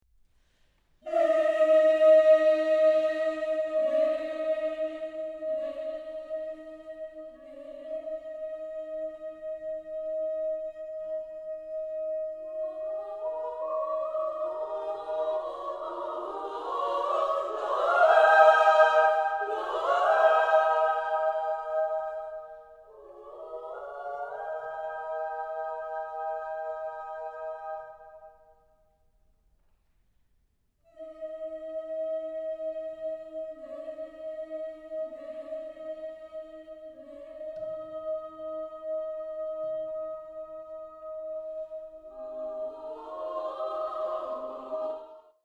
Genre-Stil-Form: Poetisches Lied ; weltlich
Charakter des Stückes: melancholisch
Chorgattung: SSA  (3 Frauenchor Stimmen )